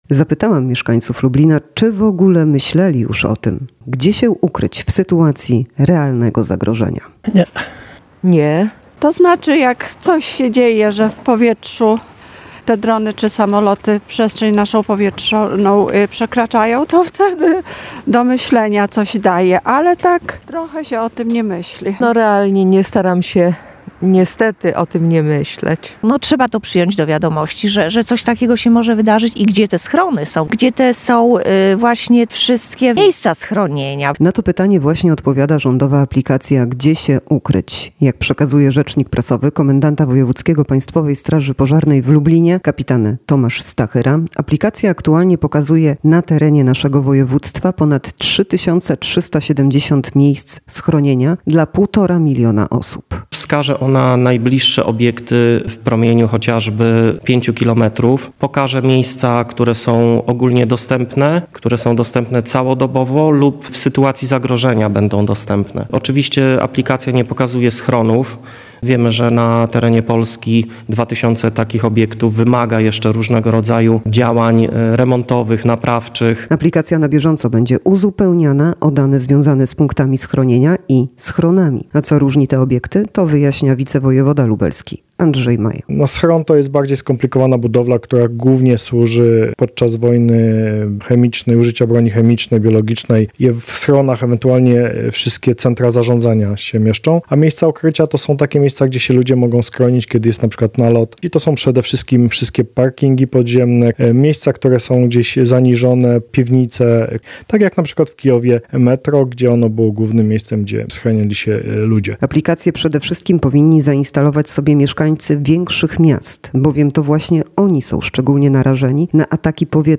Zapytaliśmy mieszkańców Lublina, czy myśleli o tym, gdzie się ukryć w sytuacji realnego zagrożenia.